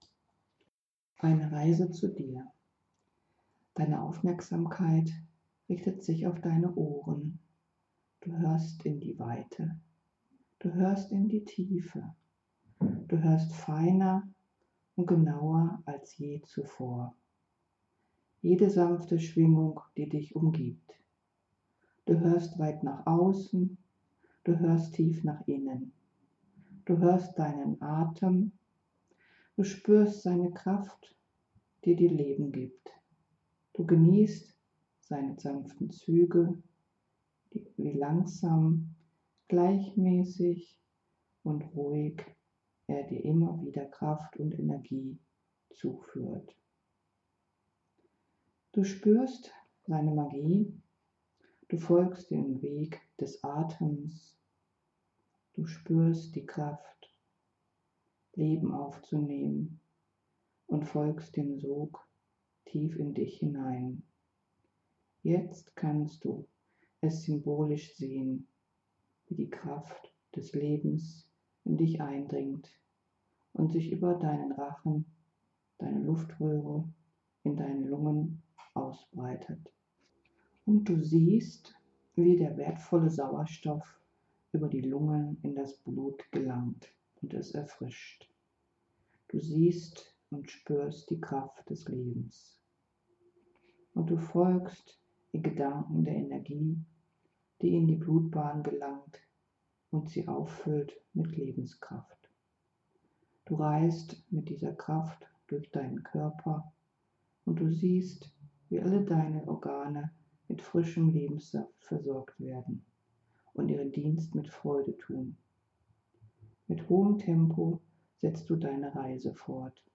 Eine besondere Meditation: Eine Reise zu Dir! (mp3)